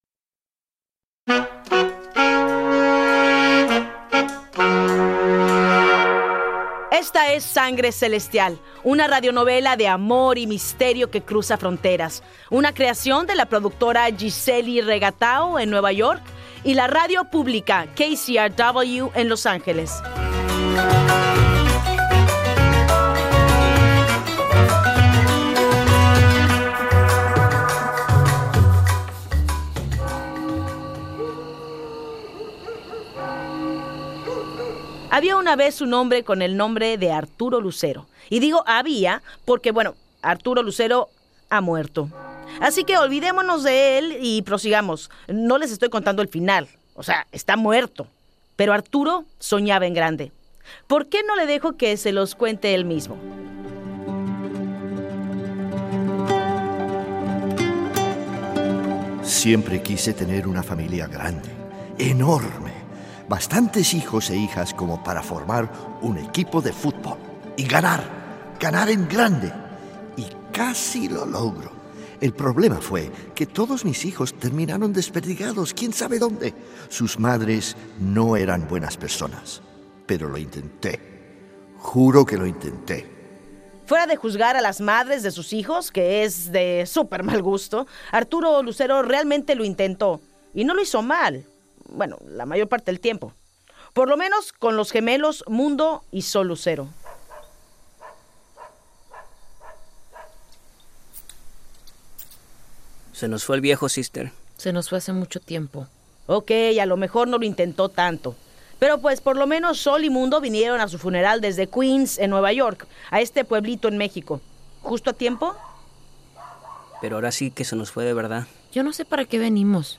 Kate del Castillo: Narradora